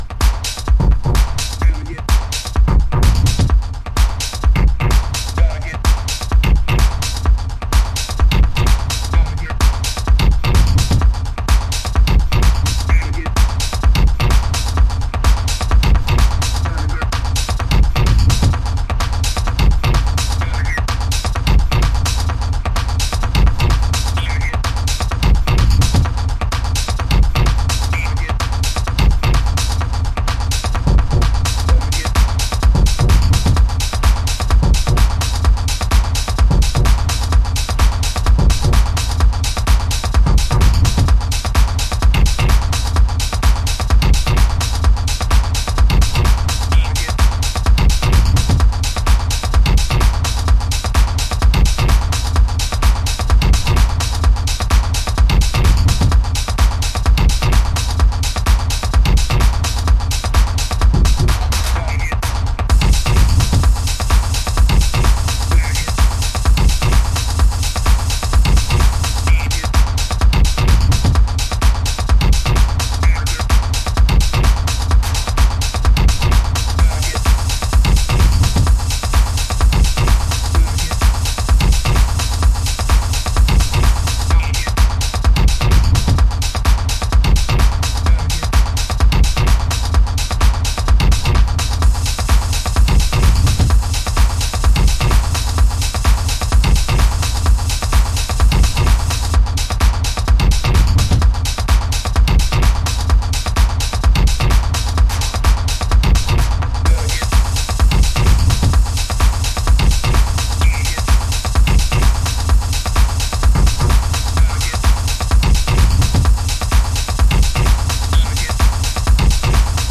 Bサイドはテクノ化しています。